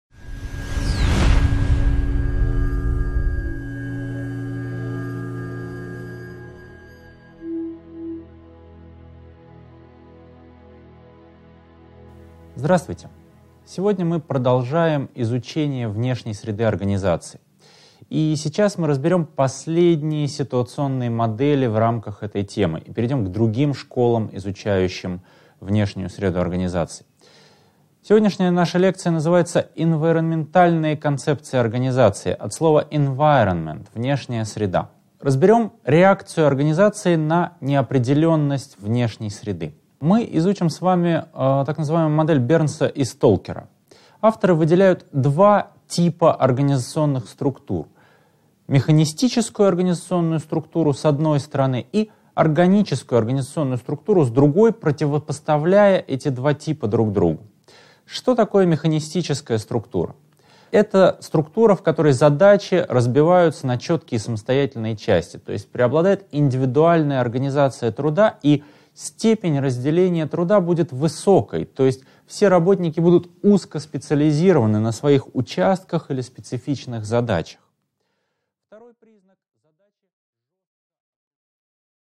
Аудиокнига 7.1. Модель Бернса и Сталкера | Библиотека аудиокниг